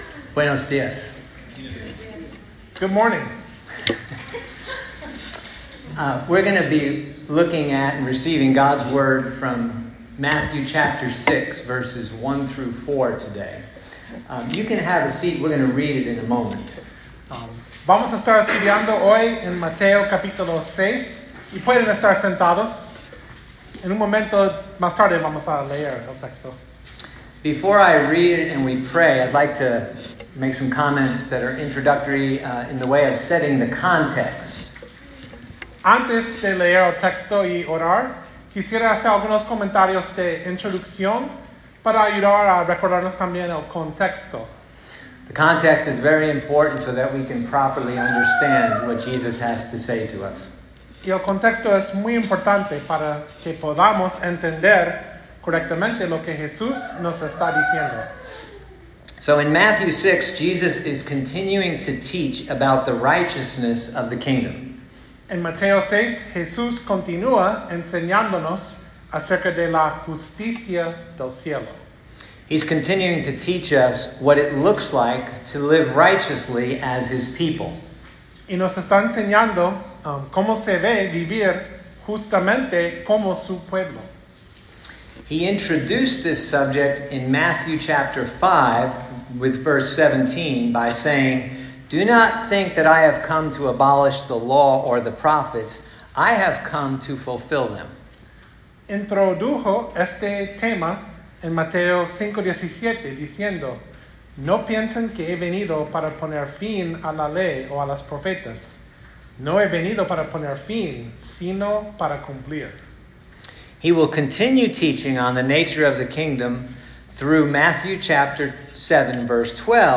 Sermon-del-Monte-08.mp3